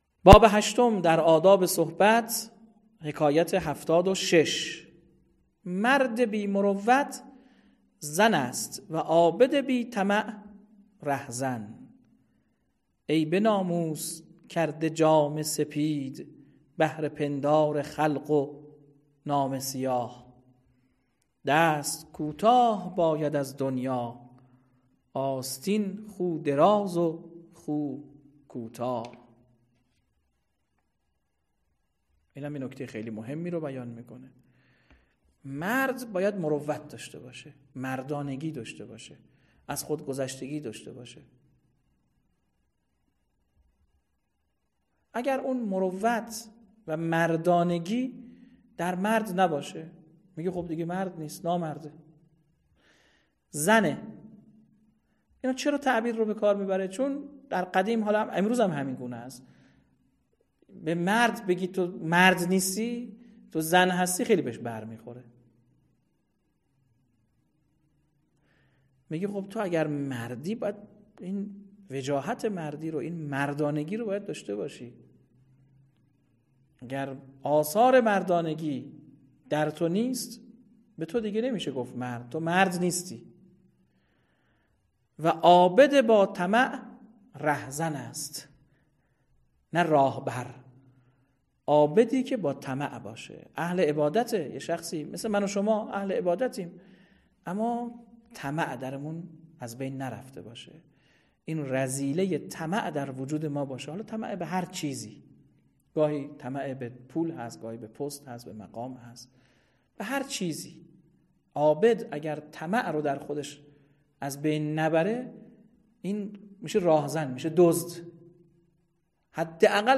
متن و خوانش حکایت 91 باب هشتم گلستان سعدی